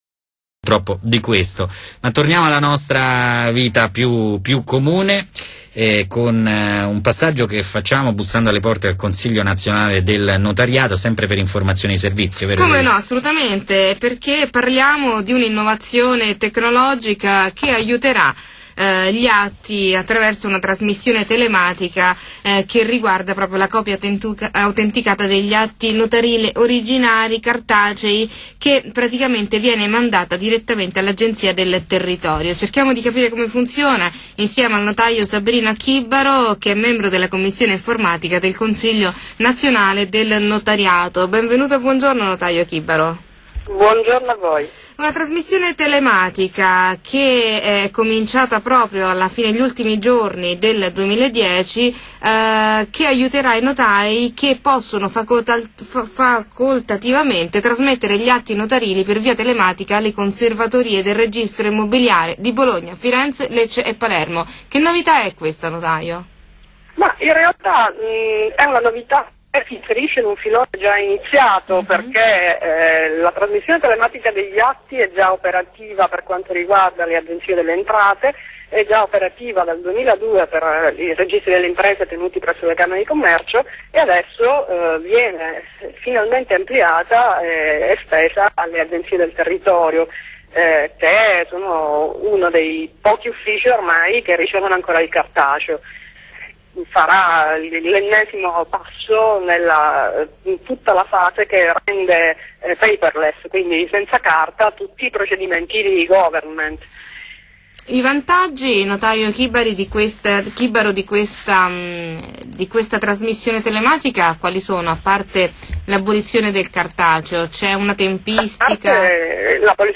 A proposito di trascrizione telematica, intervista a Nuova Spazio Radio del 3 gennaio 2011